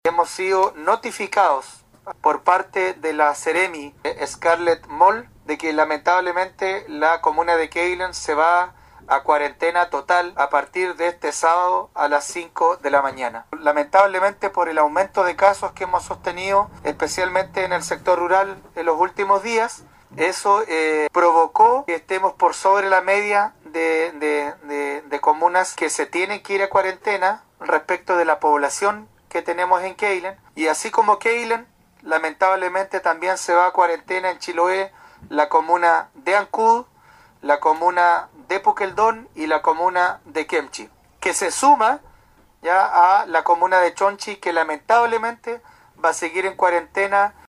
20-ALCALDE-QUEILEN.mp3